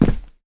landing sound
jumpland5c.wav